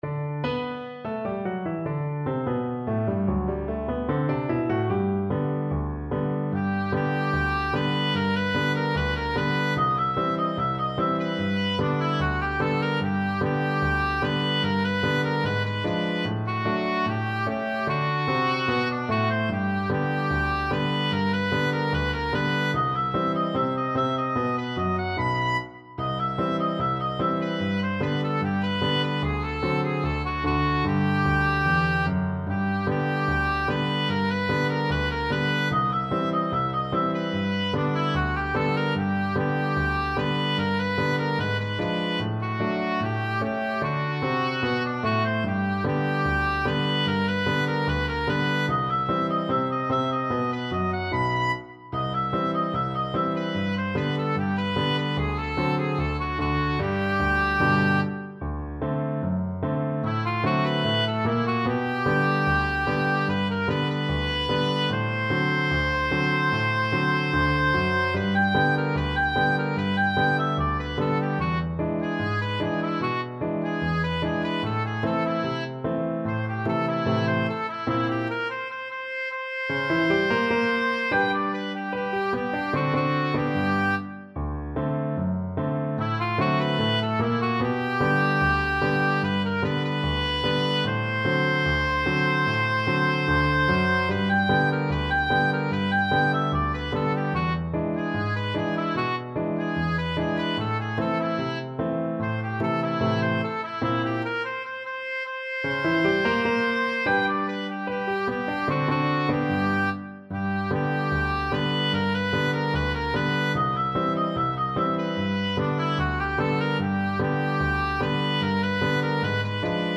Oboe 1Oboe 2
Not Fast = 74
2/4 (View more 2/4 Music)
Arrangement for Two Oboes and Piano
Jazz (View more Jazz Oboe Duet Music)